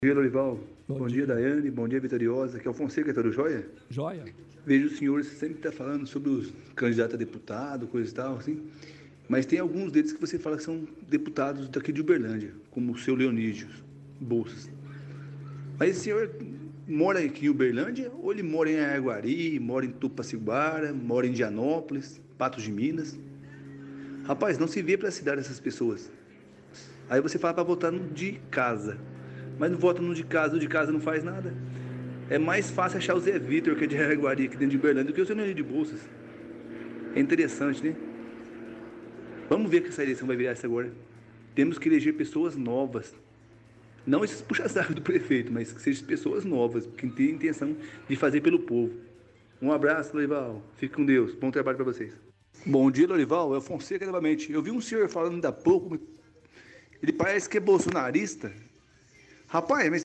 – Ouvinte diz que precisa eleger pessoas novas “não esses puxa-sacos do prefeito”.
Ouvinte-diz-que-precisa-eleger-pessoas-novas.mp3